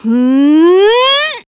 Ripine-Vox_Casting3.wav